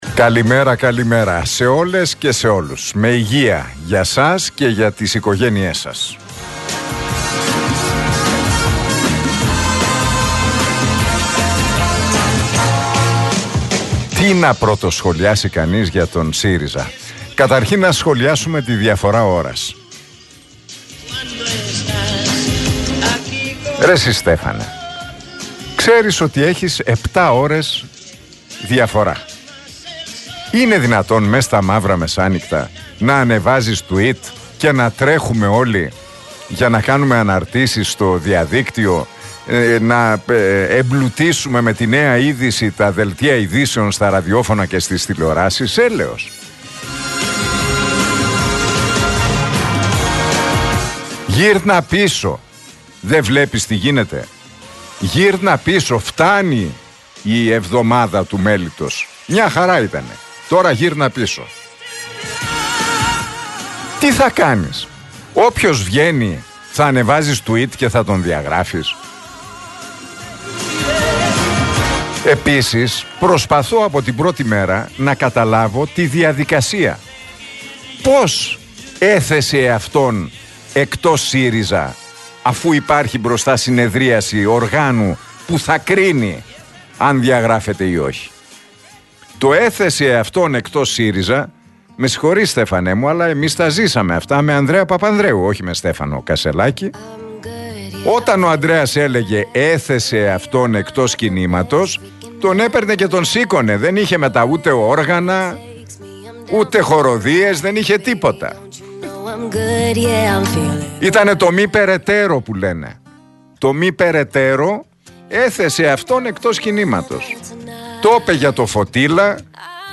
Ακούστε το σχόλιο του Νίκου Χατζηνικολάου στον RealFm 97,8, την Τρίτη 24 Οκτωβρίου 2023.